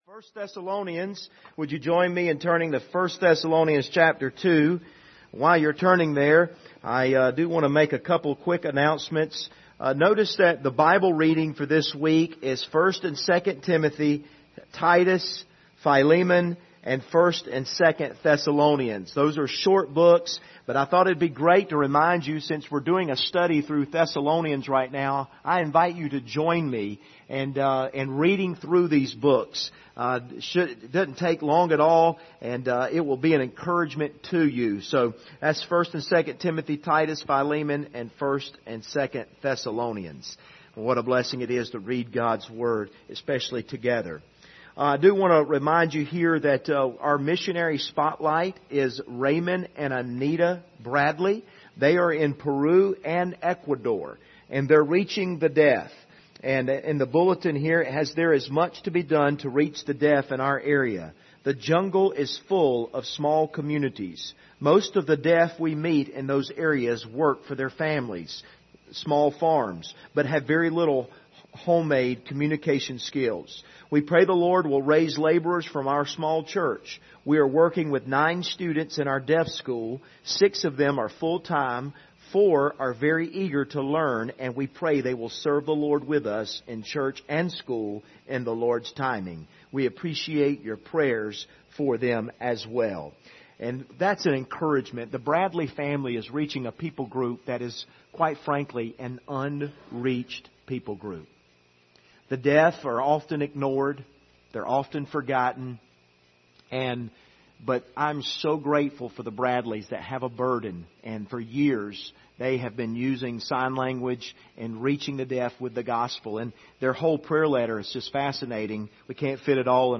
Passage: 1 Thessalonians 2:7-12 Service Type: Sunday Evening